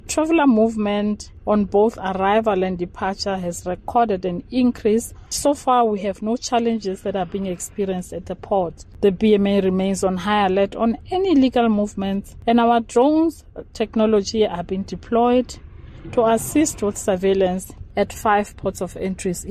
Die owerheid se adjunk-kommissaris, Mmemme Mogotsi, sê daar is nie meer toue vragmotors nie en die normale verwerking van reisigers is hervat.